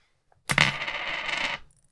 Coin Spin - 1
buy coin coins ding drop dropping falling finance sound effect free sound royalty free Sound Effects